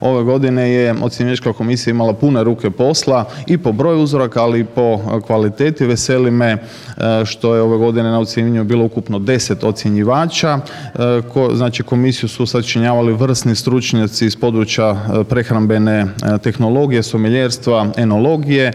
ROVINJ - Drugi GinIstra Festival sve je bliže pa je tim povodom ovog utorka u Rovinju održana konferencija za medije na kojoj je predstavljeno ovogodišnje izdanje Festivala, a koje će se upravo u Rovinju, u Staroj tvornici duhana, održati ovog vikenda - u petak i subotu.